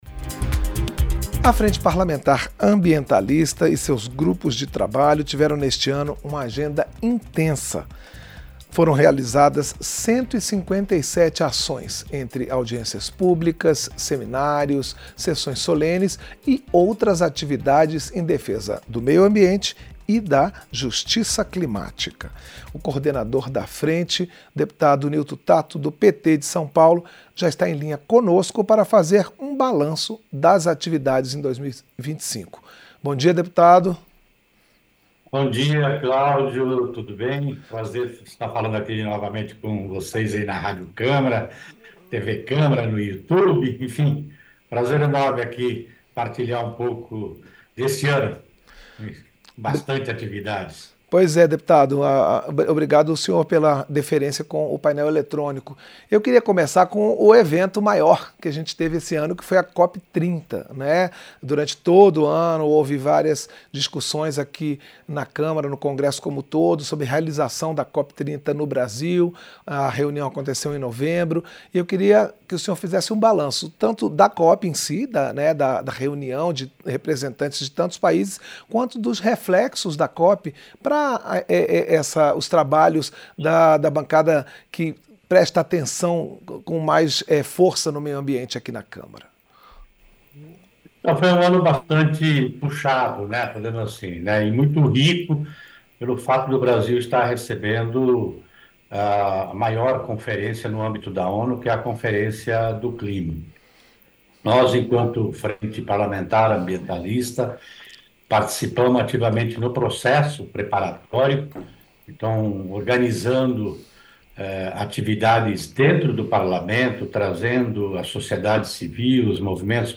Entrevista - Dep. Nilto Tatto (PT-SP)